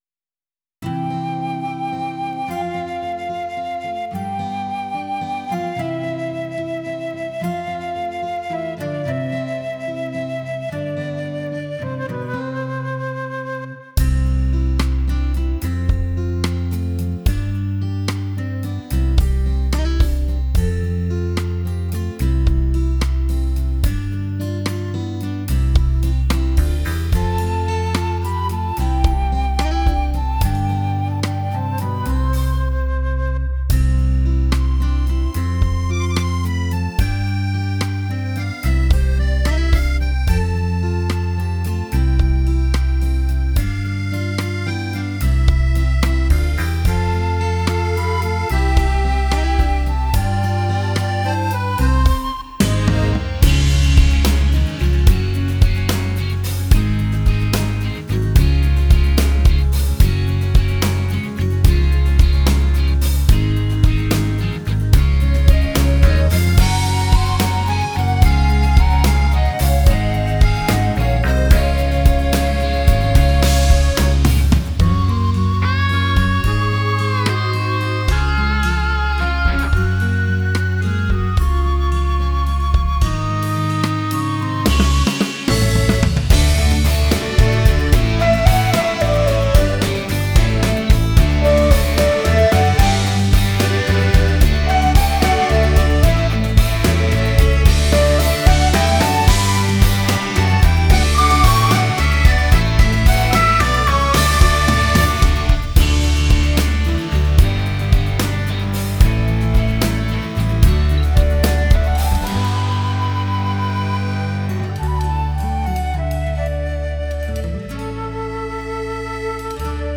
Скачать минус детской песни